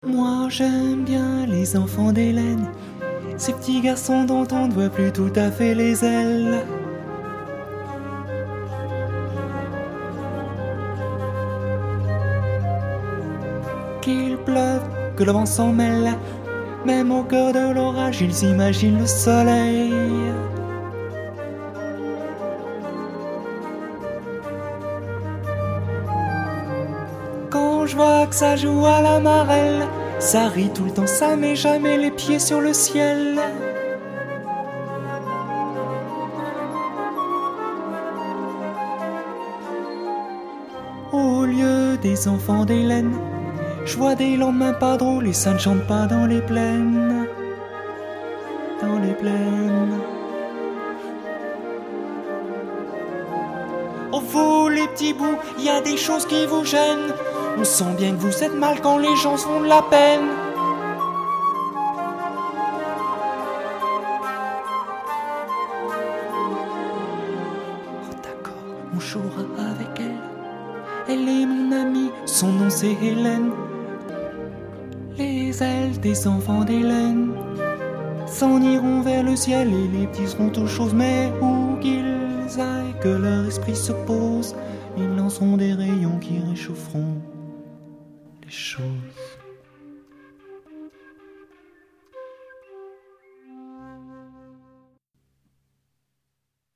chant,claviers.
flûte